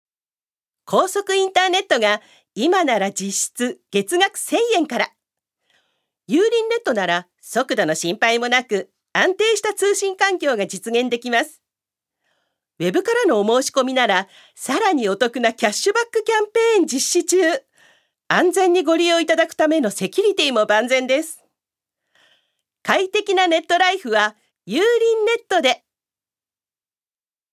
女性タレント
ナレーション１